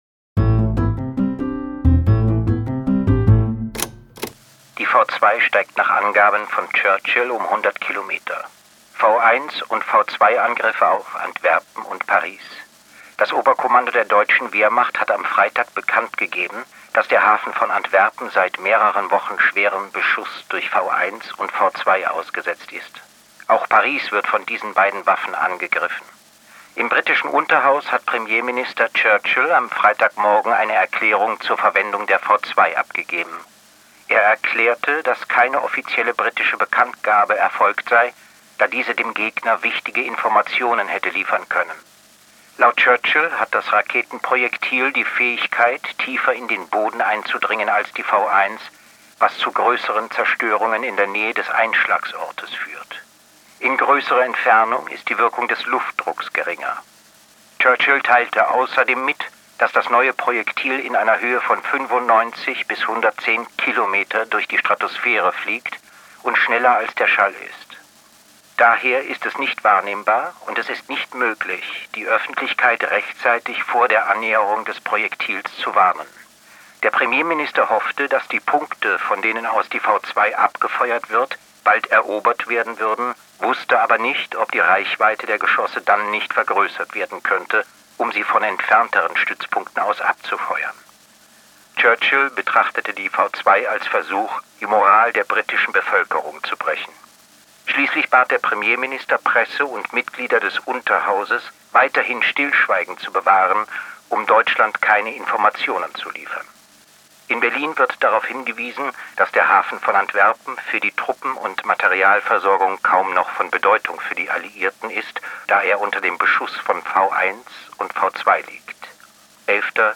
vorgetragen von Sebastian Blomberg
Sebastian-Blomberg-Die-V2-mit-Musik.m4a